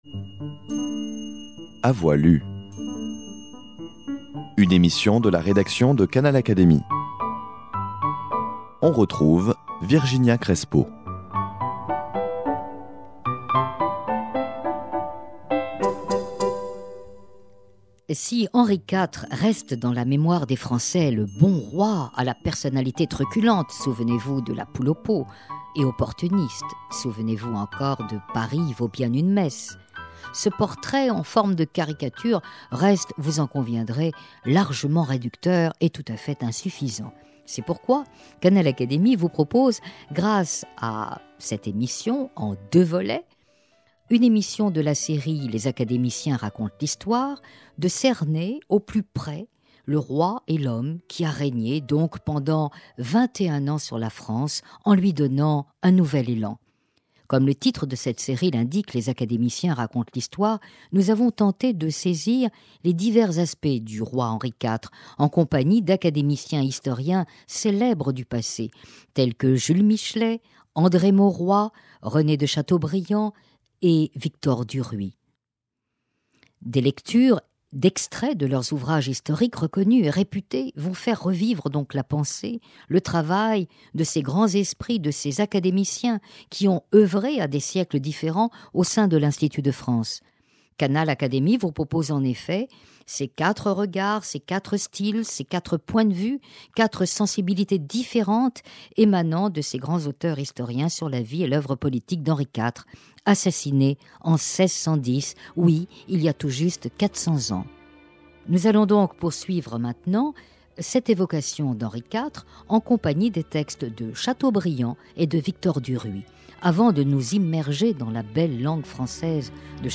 Après André Maurois et Jules Michelet, écoutez ici des extraits des œuvres historiques de Chateaubriand et de Victor Duruy.